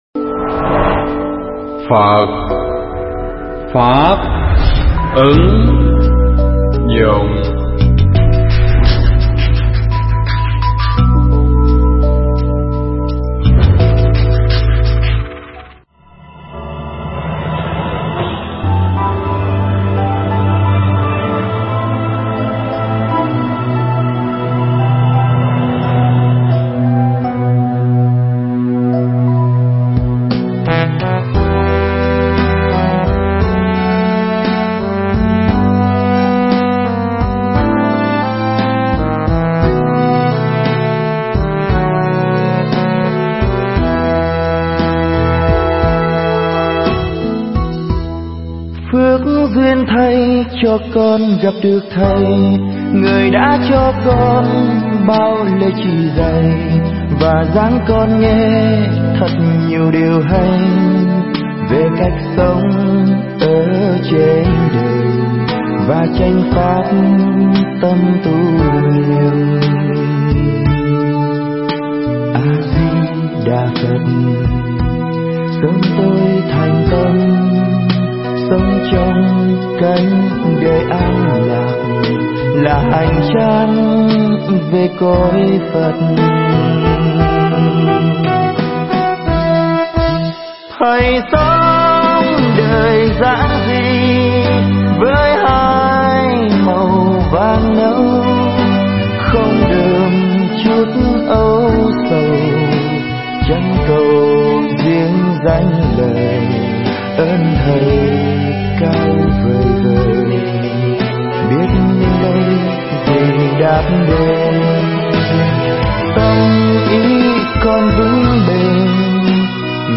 Nghe Mp3 thuyết pháp Niệm Phật Chân Chánh